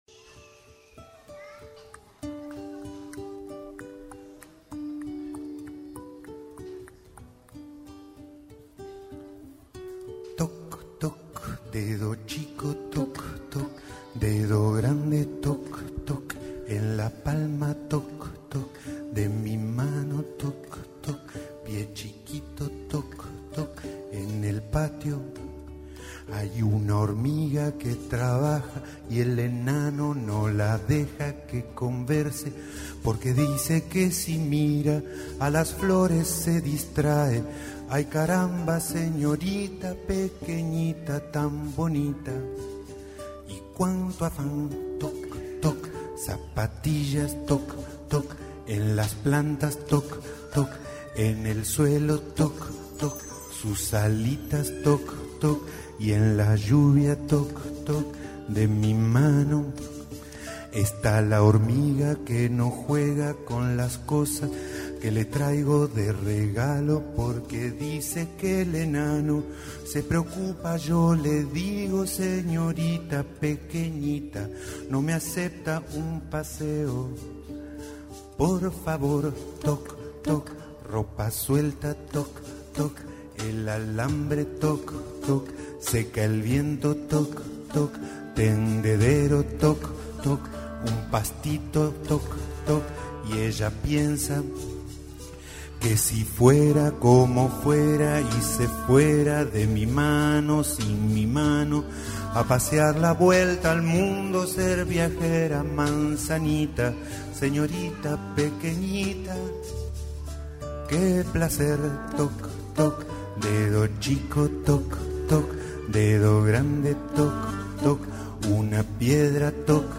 Cavaquinho
Contrabajo
voz
Grabado en vivo